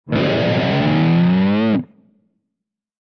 Descarga de Sonidos mp3 Gratis: guitarra a 8.